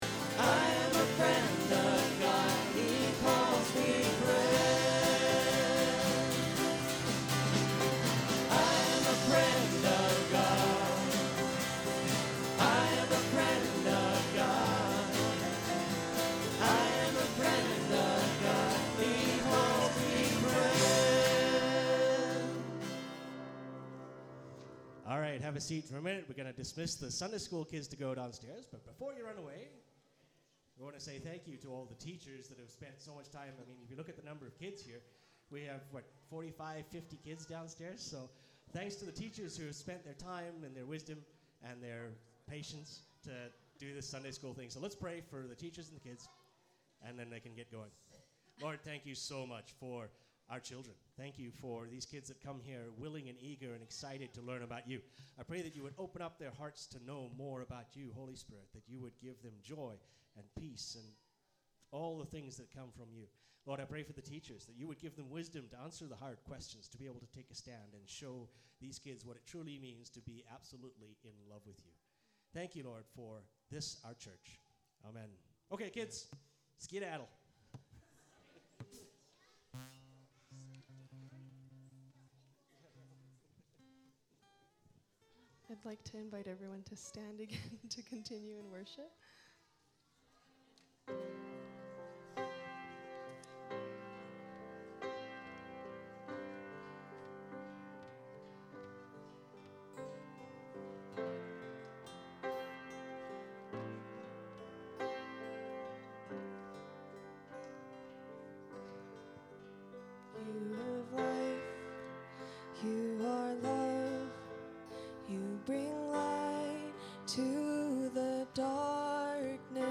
Archived Sermons | Crescent Heights Baptist Church